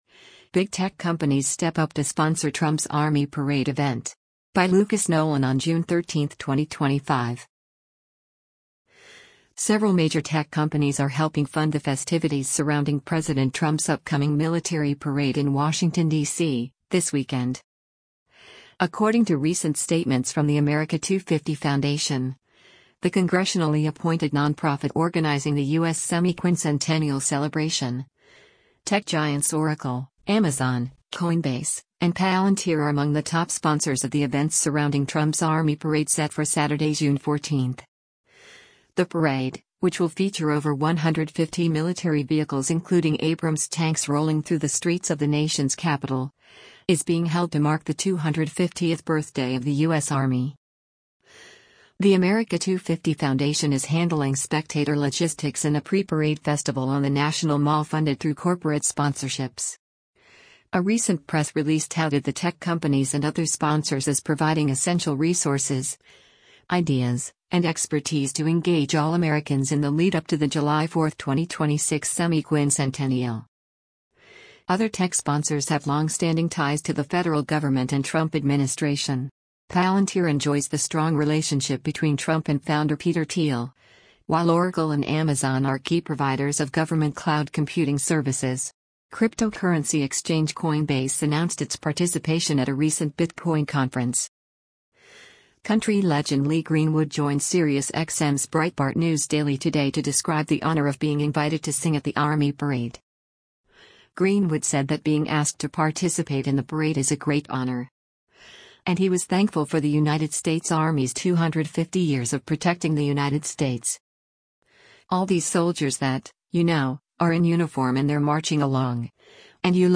Country legend Lee Greenwood joined SiriusXM’s Breitbart News Daily today to describe the honor of being invited to sing at the army parade: